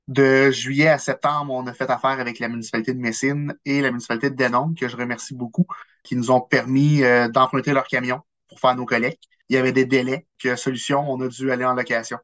Le camion de la Municipalité a atteint sa durée de vie utile en juillet. Jusqu’en septembre, Cayamant a pu compter sur le soutien de Messines et de Denholm, tel que l’indique le maire de Cayamant, Nicolas Malette :